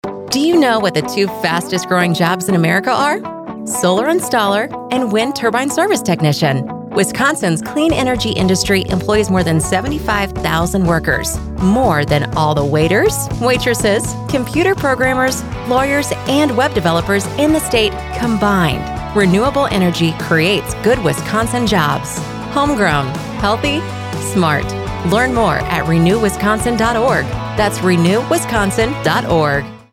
We launched a statewide campaign to educate Wisconsin about the value of homegrown, healthy, smart energy. We hope you saw our ads in your local paper and heard our messages on your favorite radio station.